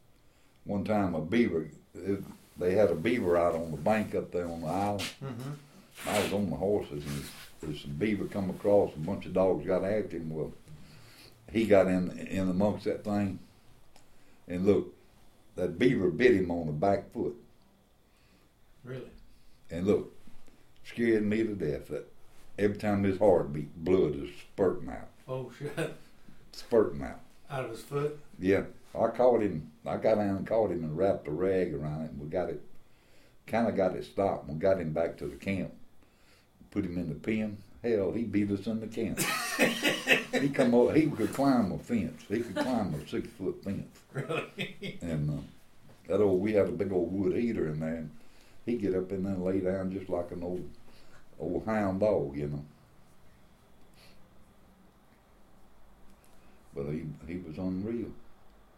Hear an Audio story of a man talking about his cherished dog .